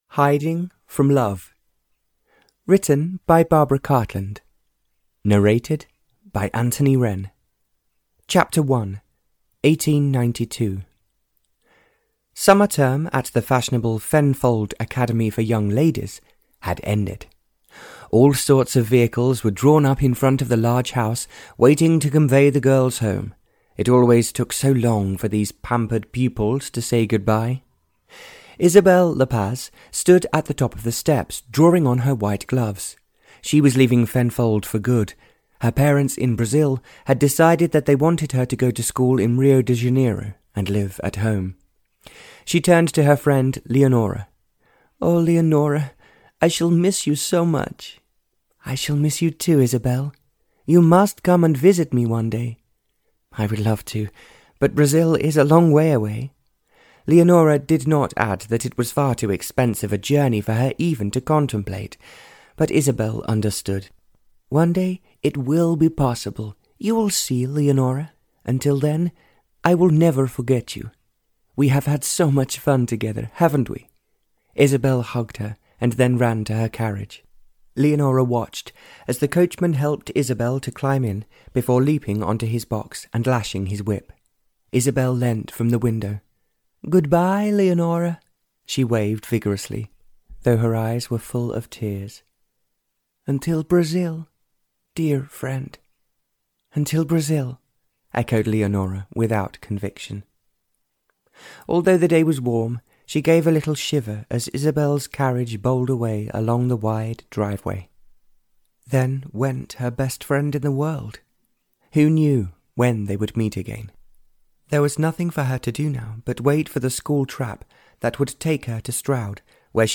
Audio knihaHiding From Love (Barbara Cartland’s Pink Collection 70) (EN)
Ukázka z knihy